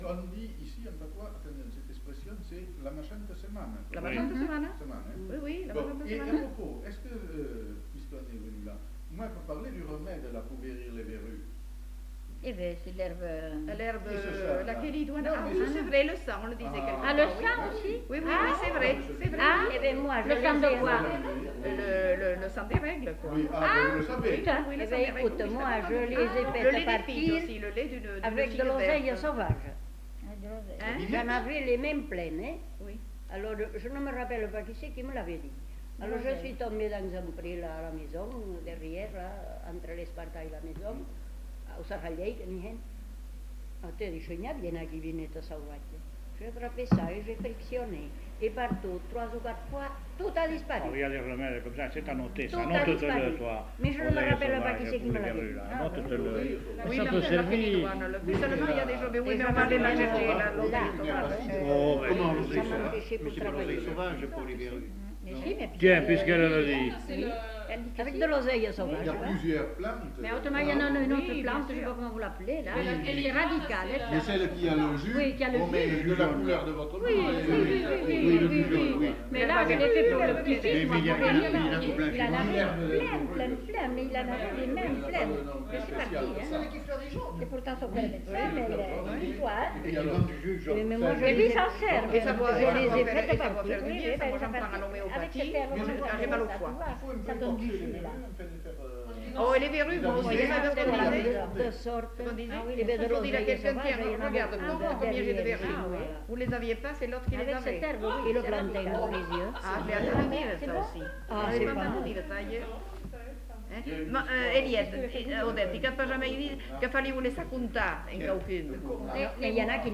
Lieu : Uzeste
Genre : témoignage thématique